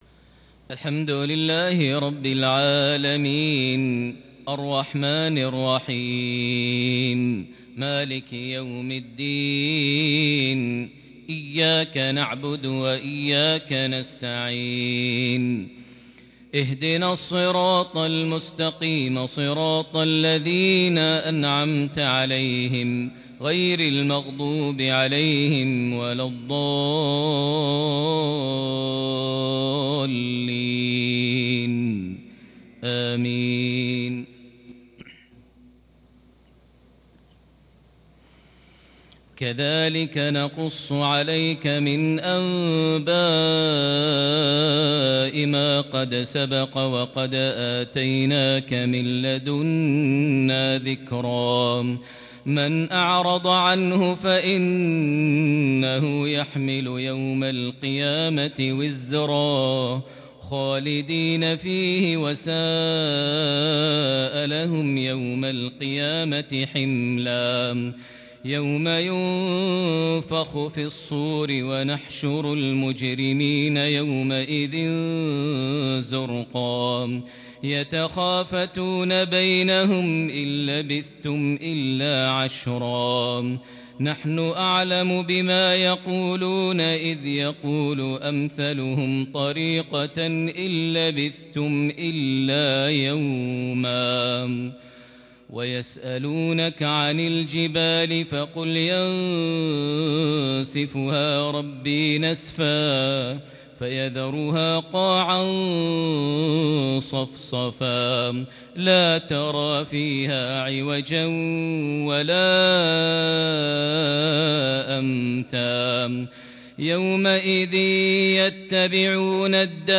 تلاوة كردية من سورة طه (99-114) | مغرب 23 ذو القعدة 1441هـ > 1441 هـ > الفروض - تلاوات ماهر المعيقلي